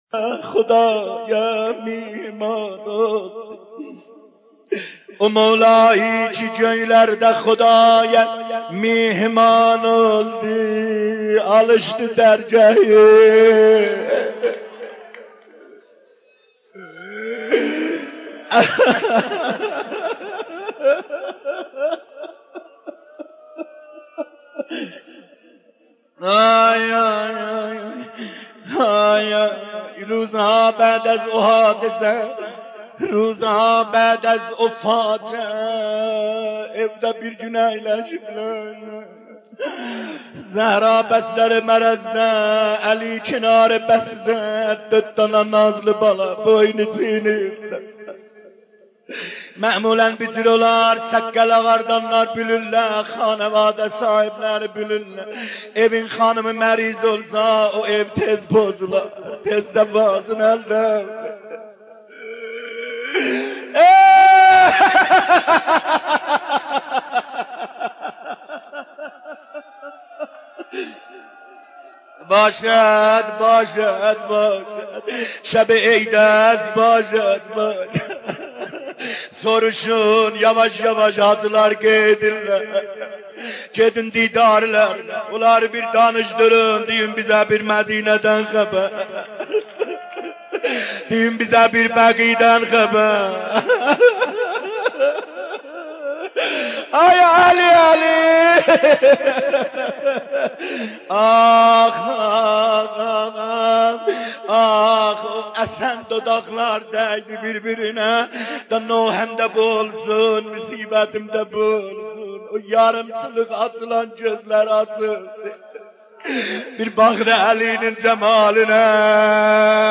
ذکر مصیبت مادر سادات، حضرت زهرا(س)